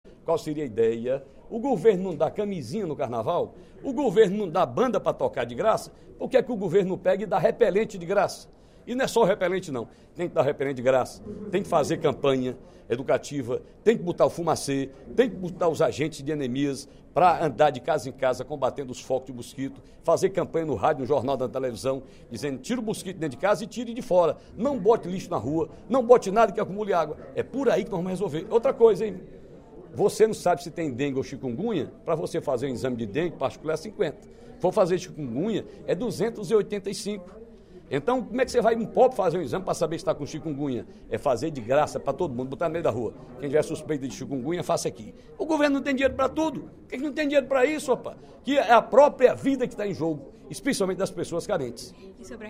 O deputado Ferreira Aragão (PDT) defendeu, nesta quarta-feira (17/05), durante o primeiro expediente da sessão plenária, a distribuição gratuita para a população, por parte do Governo do Estado, de repelentes contra mosquitos.